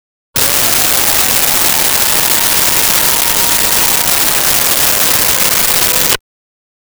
Air Horn
Air Horn.wav